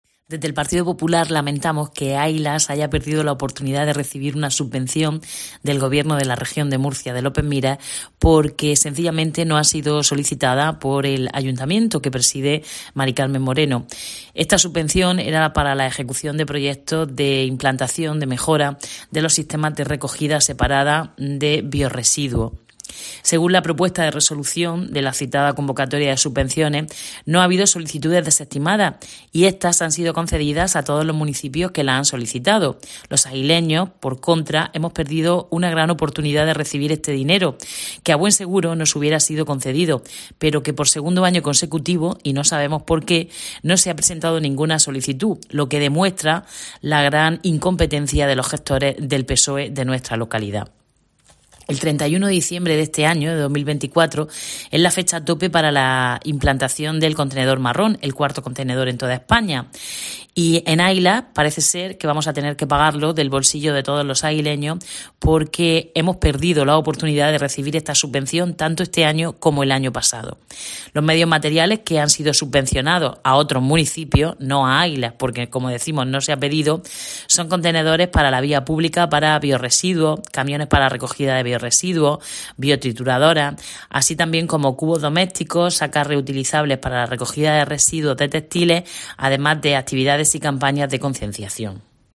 Eva Reverte, portavoz del PP en Águilas.